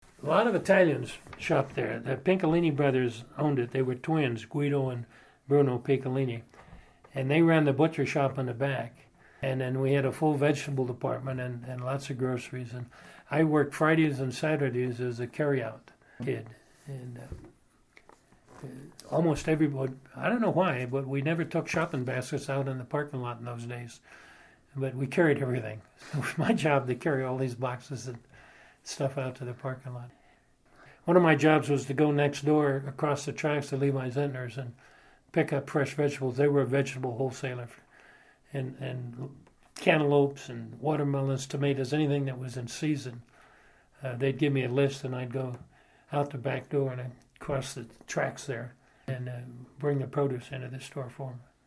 Interviewed
University of Nevada Oral History Program